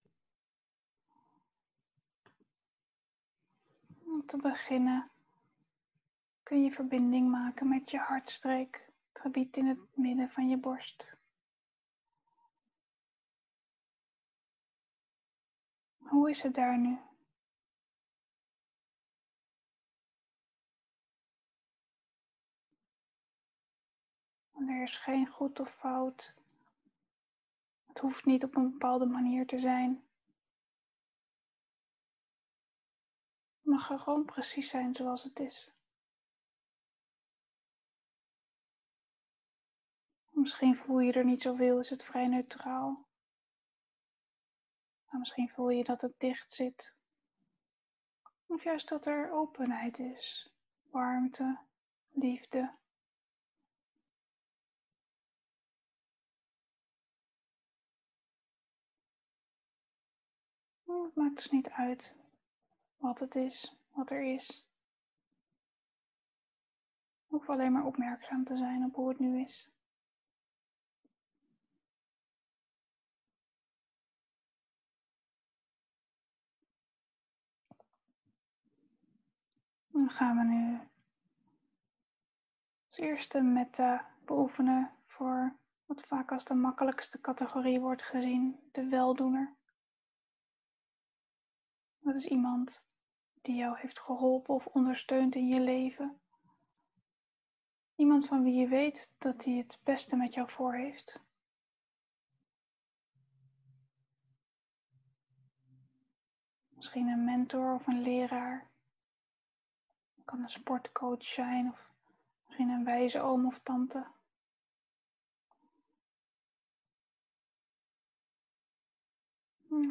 Begeleide metta-meditatie (voor weldoener, zelf en alle wezens)